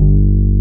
BAS.FRETG1-R.wav